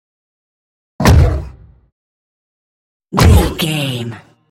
Monster creature puf hit
Sound Effects
heavy
dark
aggressive